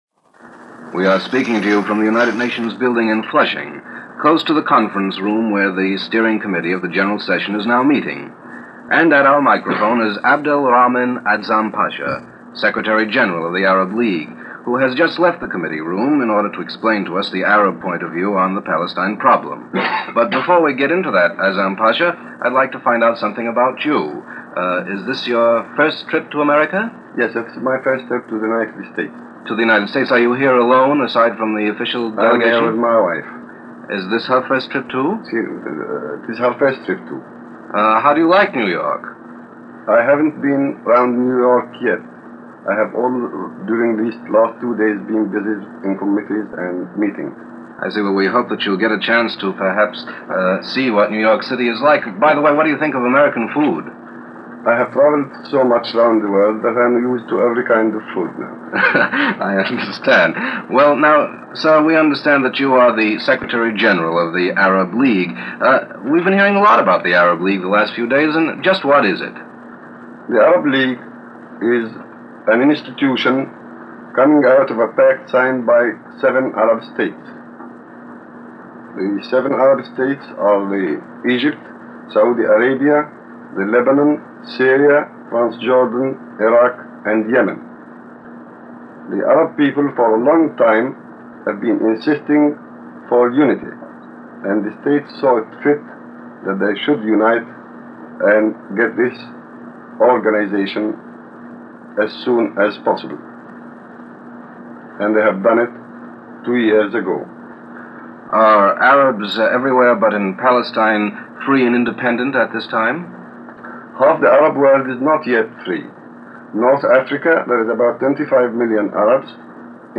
United Nations – Interview with Abdul Rahman Azzam Pasha – April 30, 1947 – Gordon Skene Sound Collection
During his visit to the United Nations, representing the Arab League, Azzam Pasha was briefly interviewed by United Nations radio.